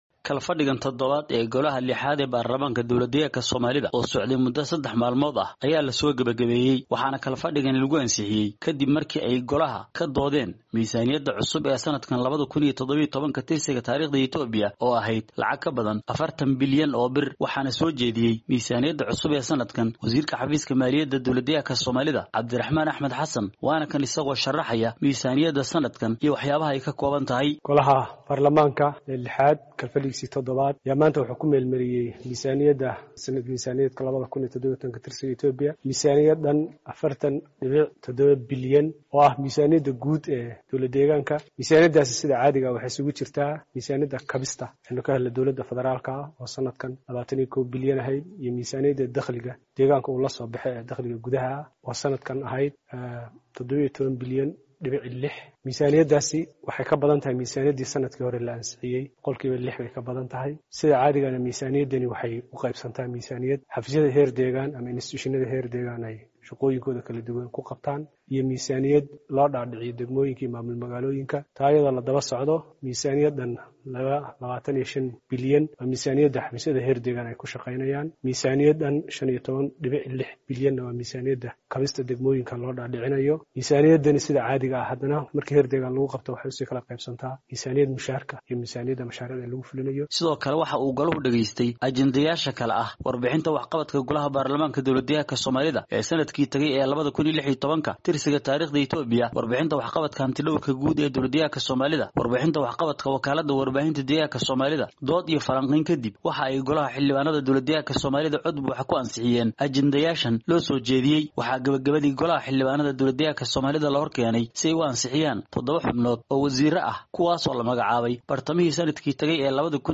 ayaa warbixintan kasoo diray